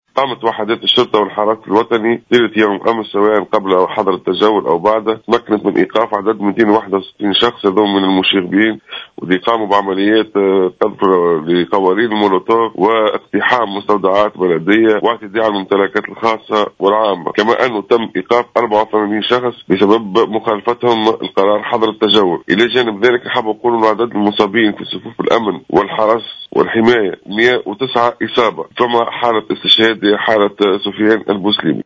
تصريح ل"الجوهرة أف أم"